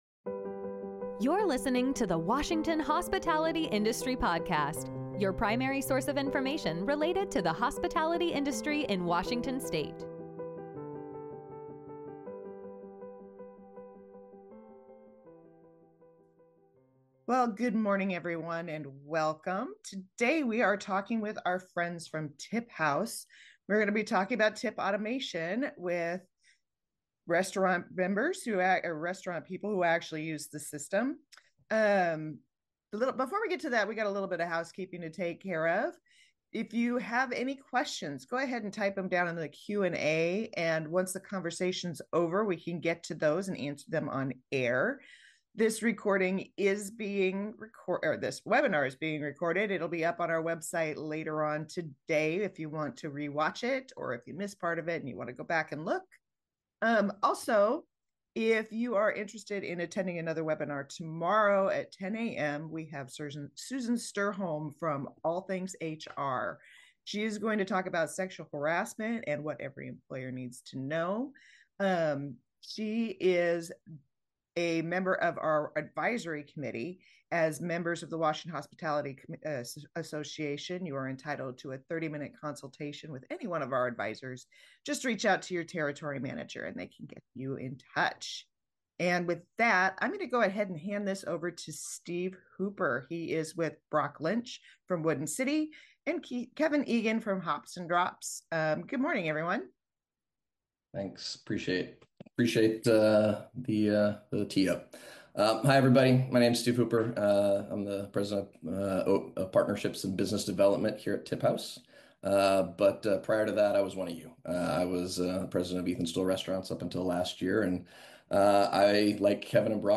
Washington Hospitality Industry Webcast - Webinar replay: Tips for managing tips and streamlining operations